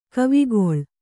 ♪ kavigoḷ